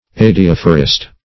Search Result for " adiaphorist" : The Collaborative International Dictionary of English v.0.48: Adiaphorist \Ad`i*aph"o*rist\ ([a^]d`[i^]*[a^]f"[-o]*r[i^]st), n. [See Adiaphorous .]